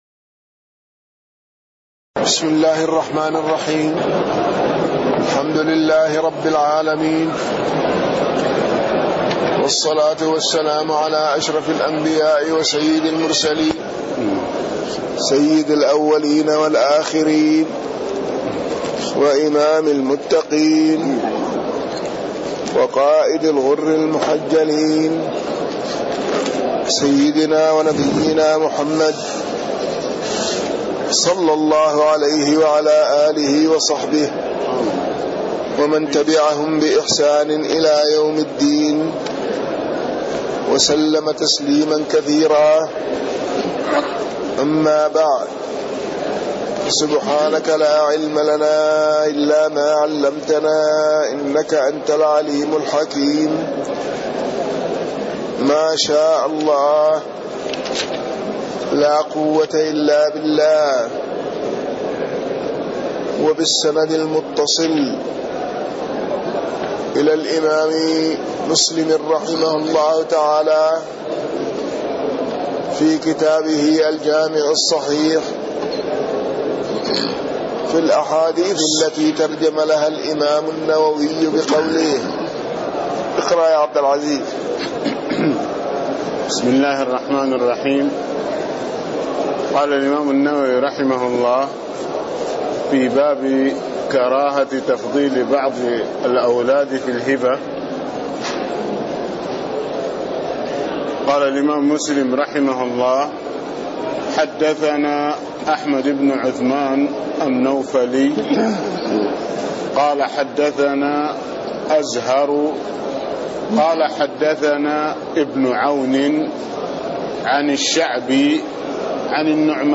تاريخ النشر ١١ ربيع الثاني ١٤٣٥ هـ المكان: المسجد النبوي الشيخ